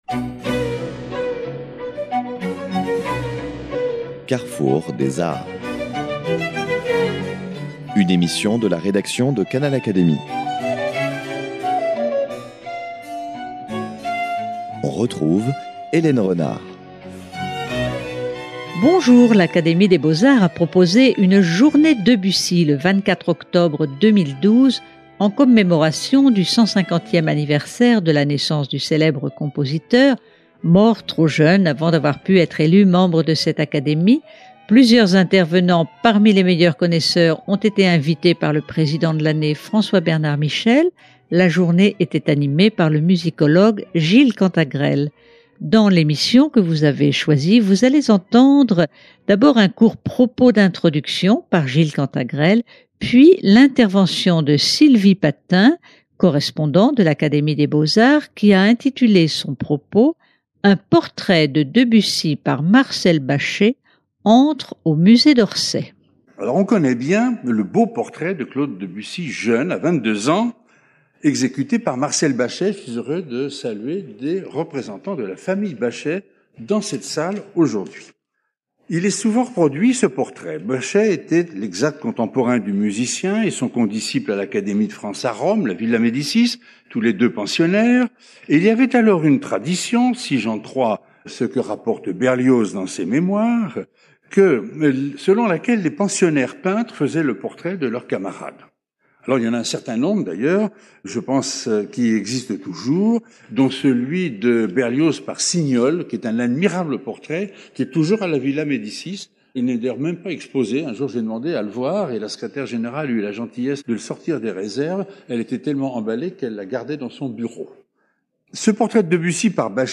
L’Académie des beaux-arts a proposé une "Journée Debussy" le 24 octobre 2012, en commémoration du 150è anniversaire de la naissance du célèbre compositeur, mort trop jeune juste avant d’avoir pu être élu membre de cette académie.